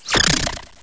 pokeemerald / sound / direct_sound_samples / cries / uncomp_sinistea.aif
uncomp_sinistea.aif